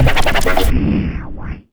4606L SCRACH.wav